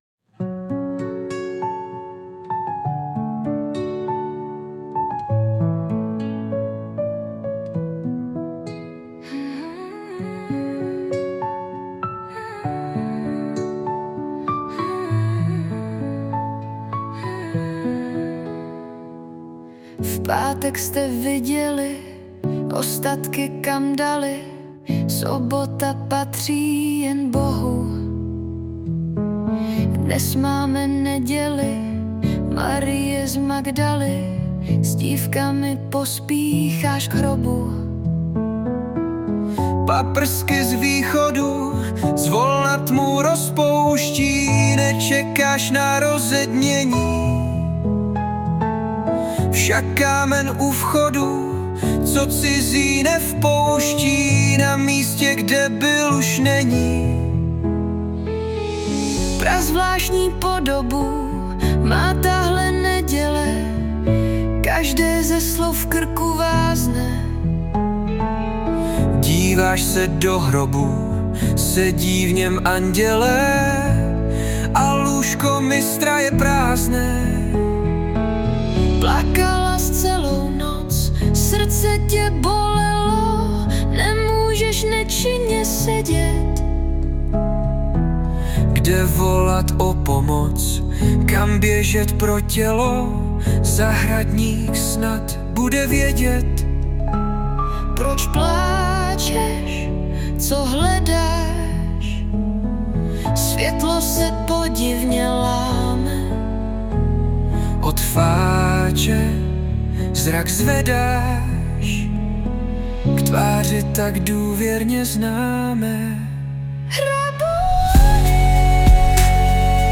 AI zhudebnění tady...